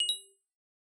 Coins (3).wav